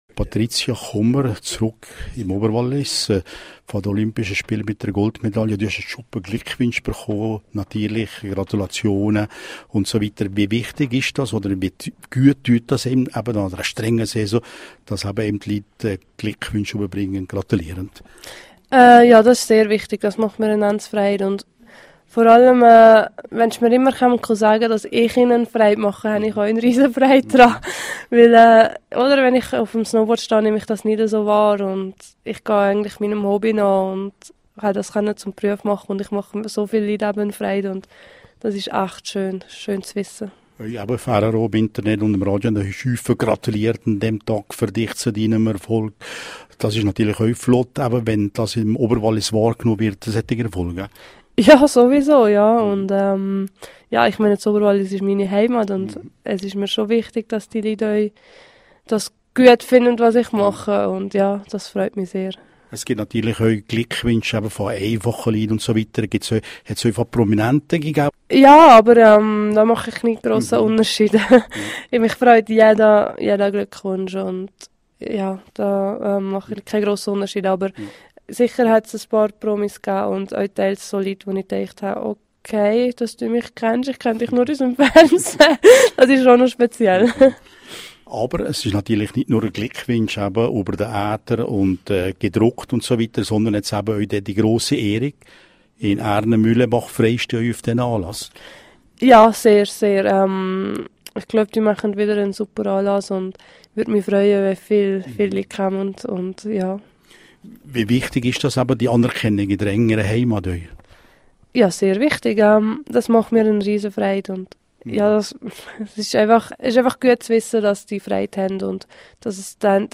Alle Sportbegeisterten im Wallis sind dazu eingeladen./en Interview mit Patrizia Kummer.